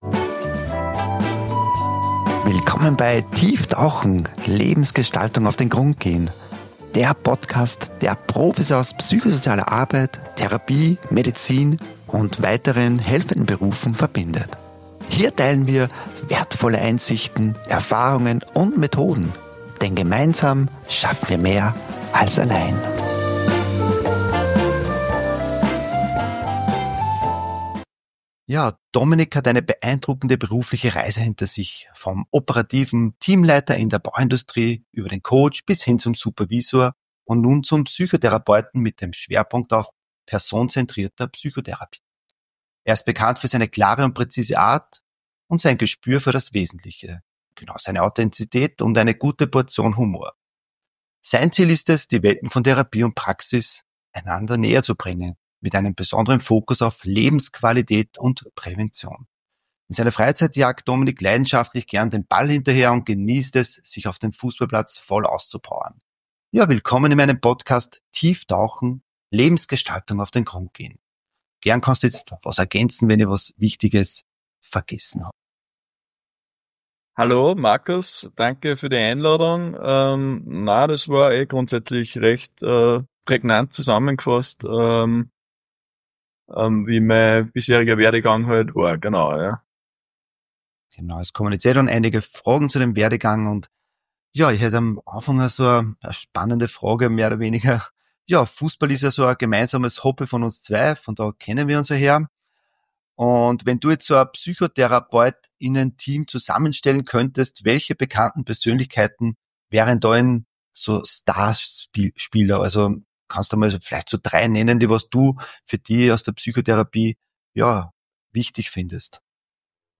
Von ehrlichen Einblicken in seinen Werdegang bis hin zu inspirierenden Ansätzen für den Alltag – dieses Interview ist ein Muss für alle, die Führungsstärke, Klarheit und Menschlichkeit verbinden möchten.